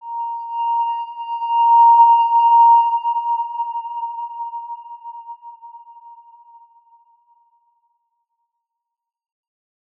X_Windwistle-A#4-mf.wav